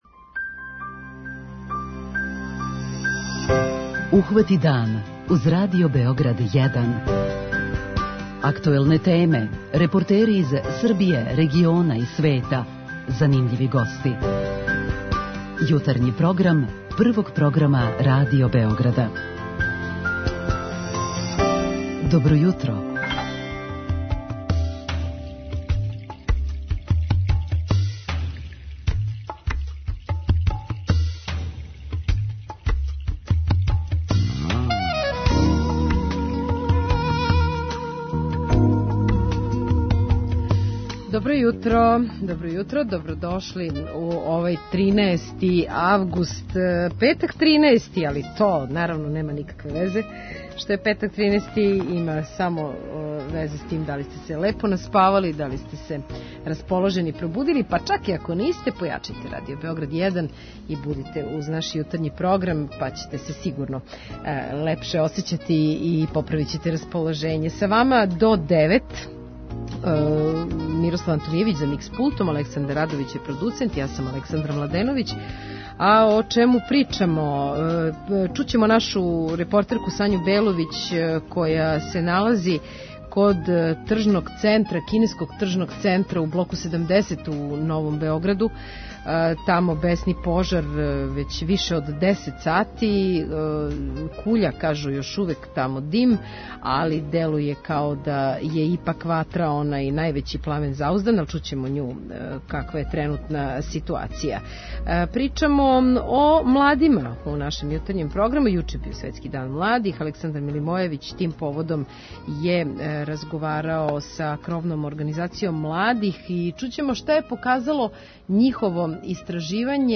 О потребама младих разговарамо и са вама у Питању јутра.